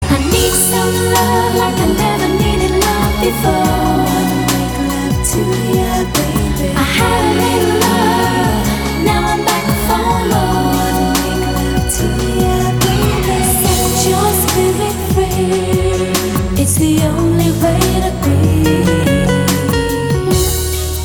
• Качество: 320, Stereo
поп
красивые
женский вокал
спокойные
90-е